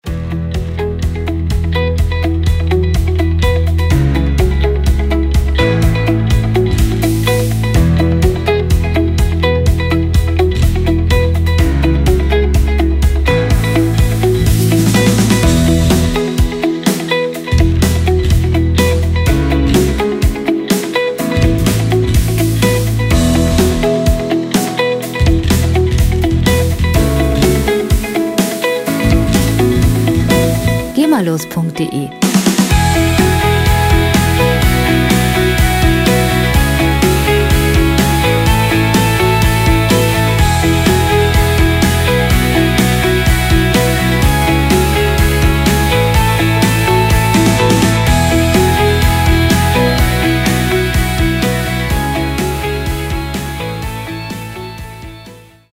lizenzfreie Werbemusik für Imagefilme
Musikstil: Pop-Rock
Tempo: 125 bpm
Tonart: E-Dur
Charakter: dynamisch, zielstrebig
Instrumentierung: E-Gitarre, E-Bass, Drums